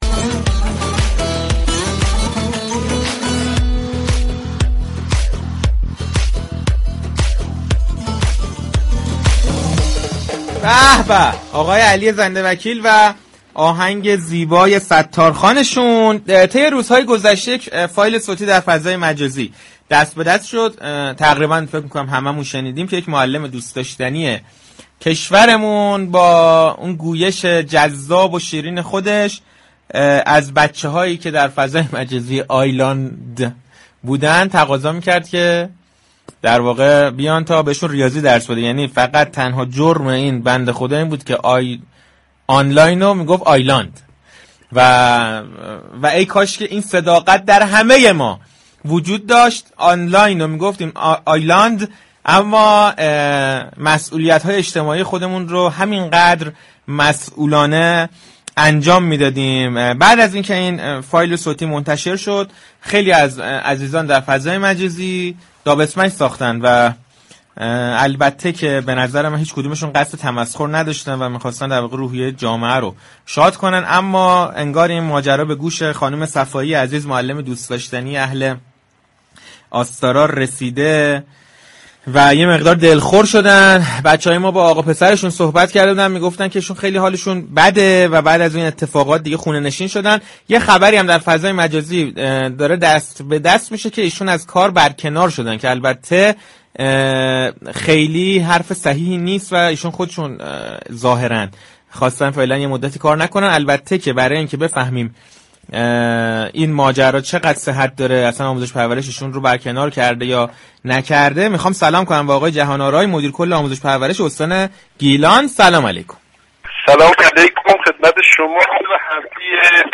پشت‌صحنه‌ای‌های رادیو تهران در گفتگویی تلفنی با مدیركل آموزش پرورش استان گیلان، جویای احوال این معلم دلسوز شده و در مورد خبر یاد شده از او سوال كردند.